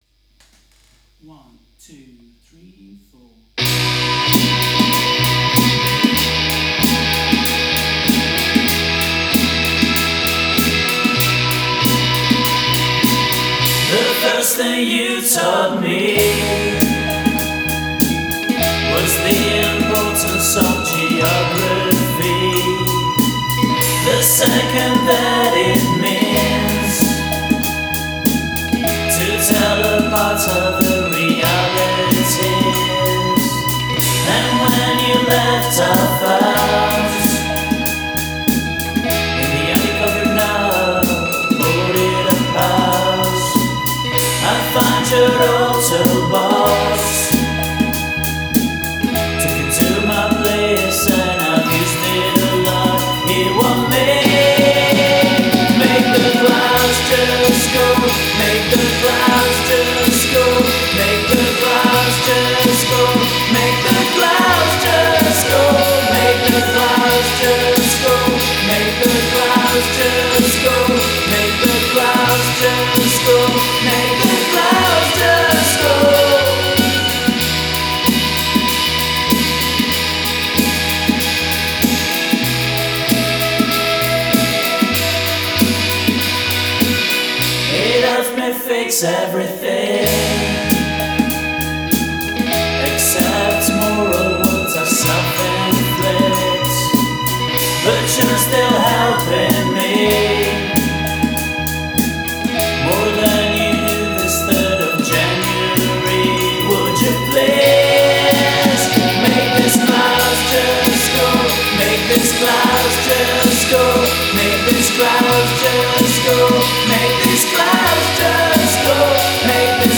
vocals, guitars, bass, keyboards, drums, percussion